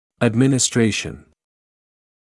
[ədˌmɪnɪs’treɪʃn][эдˌминис’трэйшн]введение или прием лекарства; назначение; применение